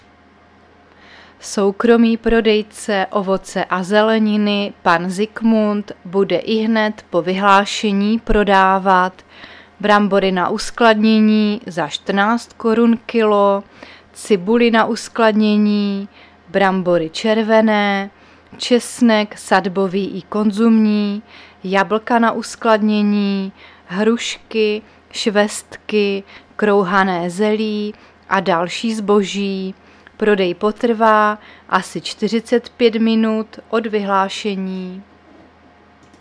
Záznam hlášení místního rozhlasu 18.10.2023
Zařazení: Rozhlas